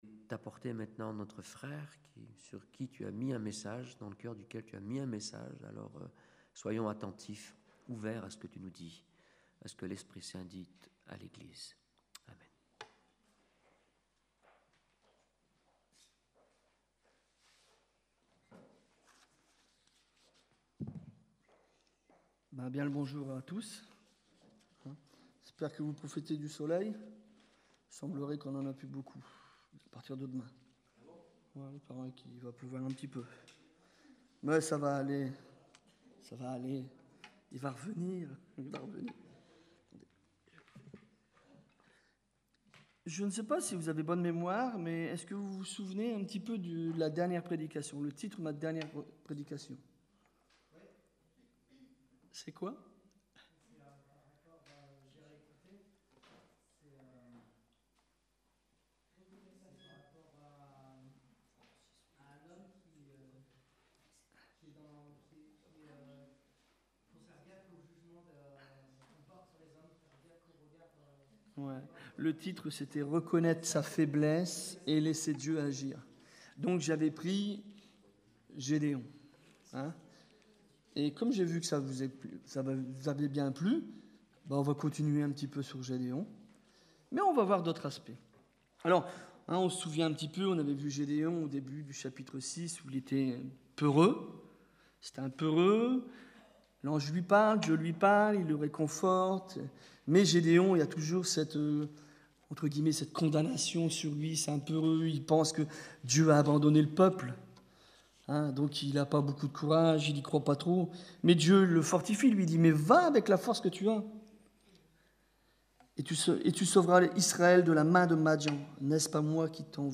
Prédications Laisse toi faire par Dieu